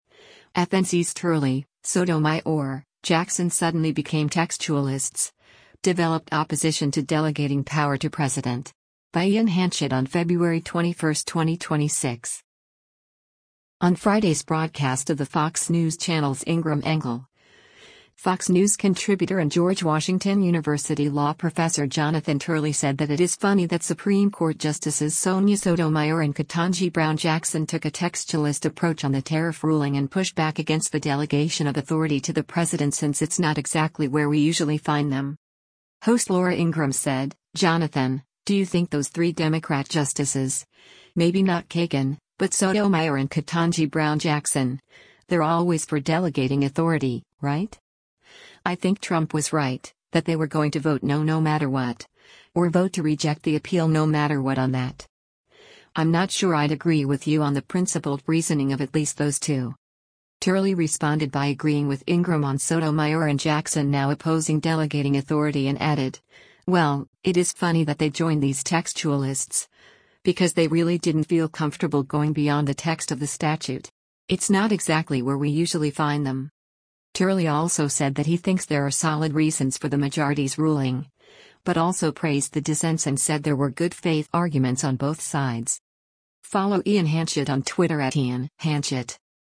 On Friday’s broadcast of the Fox News Channel’s “Ingraham Angle,” Fox News Contributor and George Washington University Law Professor Jonathan Turley said that “it is funny” that Supreme Court Justices Sonia Sotomayor and Ketanji Brown Jackson took a textualist approach on the tariff ruling and pushed back against the delegation of authority to the president since “It’s not exactly where we usually find them.”